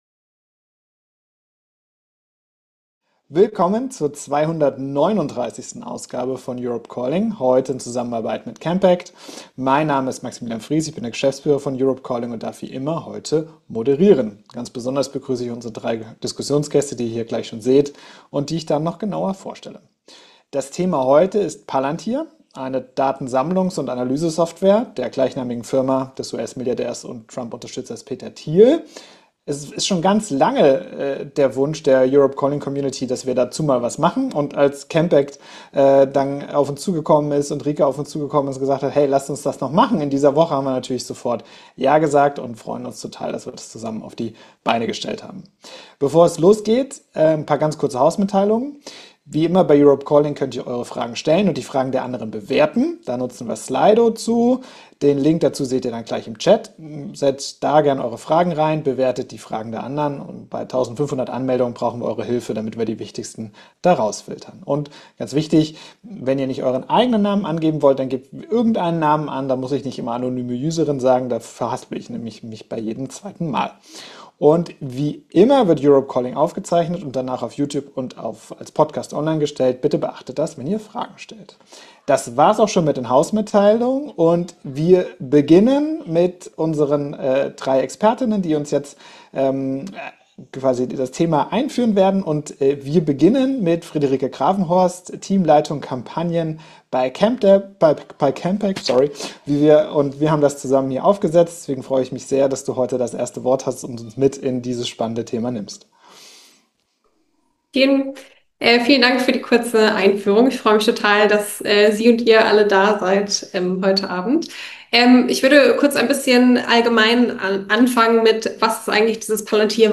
Aufzeichnung der 239. Ausgabe von Europe Calling.